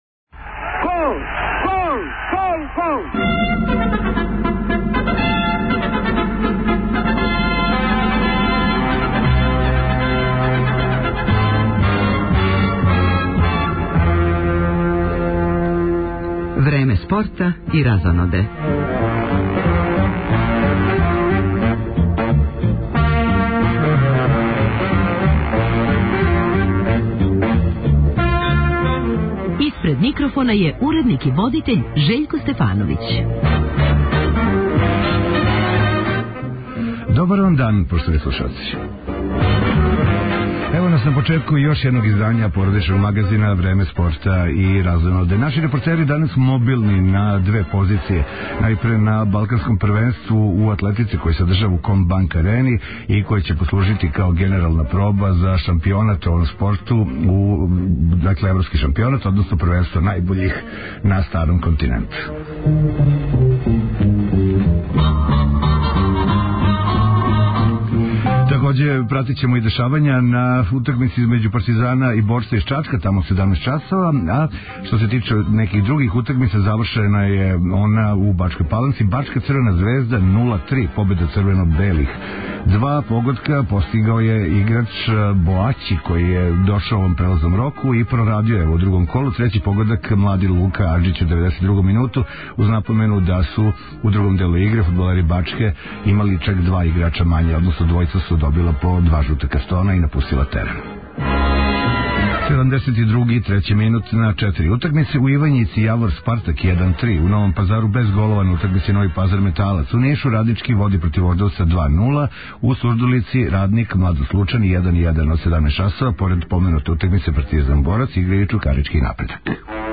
Београдска Комбанк арена домаћин је атлетског првенства Балкана, са кога ће се јављати наши репортери.
Репортери Радио Београда1 јављаће се с лица места, баш као и са фудбалске утакмице Супер лиге Србије између Партизана и Борца. Преостали део емисије резервисан је за информације из света јавног, културног и уметничког живота.